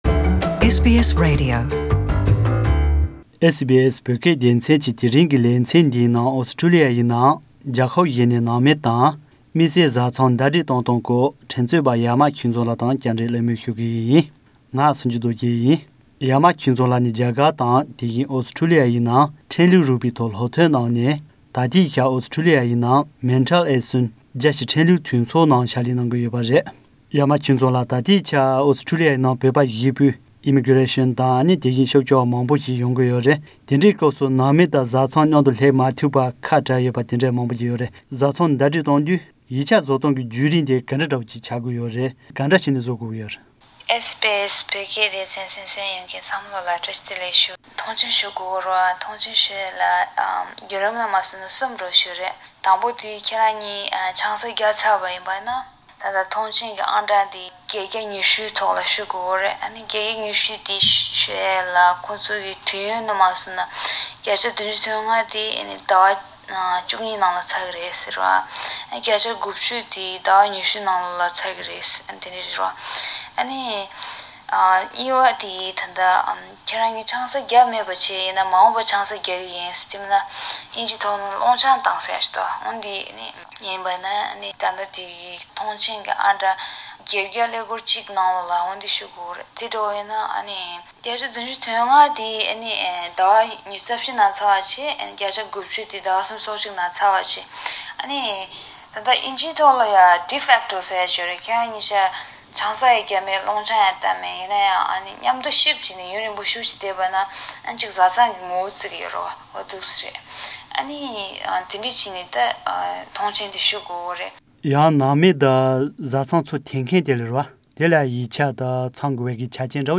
ཨོ་སི་ཊོ་ལི་ཡའི་ནང་རྒྱལ་ཁབ་གཞན་ནས་ནང་མི་དང་དམིགས་བསལ་བཟའ་ཚང་ཟླ་སྦྲེལ་གཏོང་བར་བརྒྱུད་རིམ་དང་། གྲ་སྒྲིག ཤེས་དགོས་པ་ག་རེ་ཡོད་མེད་སྐོར་བཅར་འདྲི་དང་གླེང་མོལ།